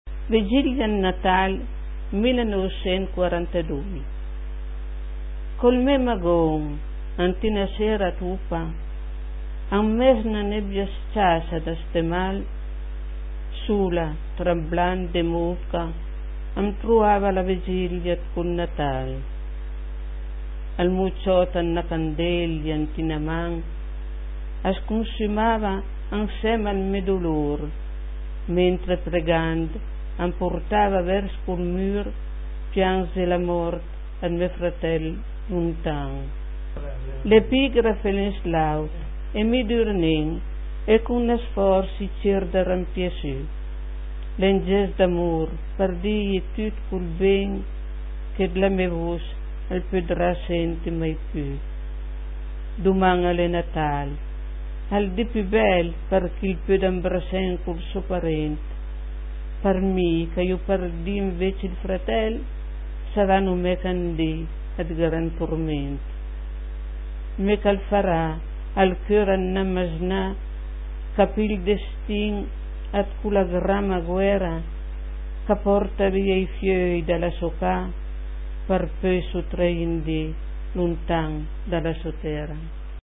Cliché chi sùta par sénti la puizìa recità da l'autùr ...